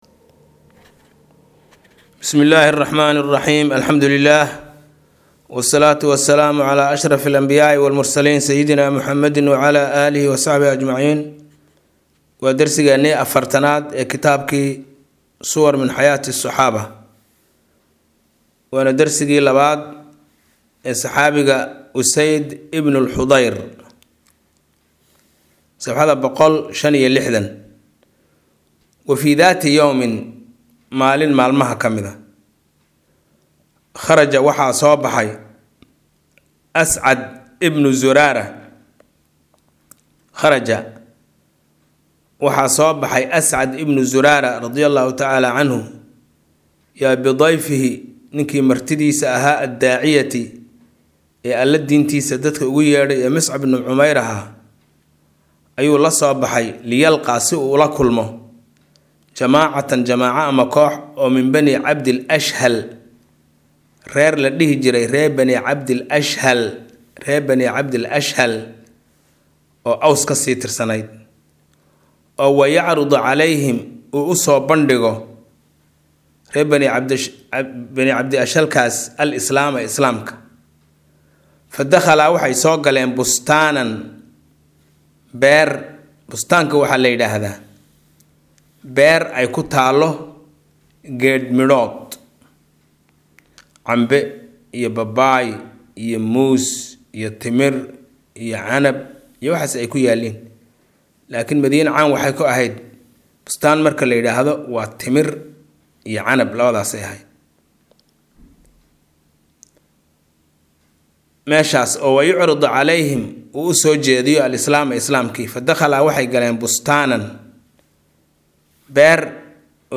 Maqal- Suwar min xayaati saxaabah- Casharka 40aad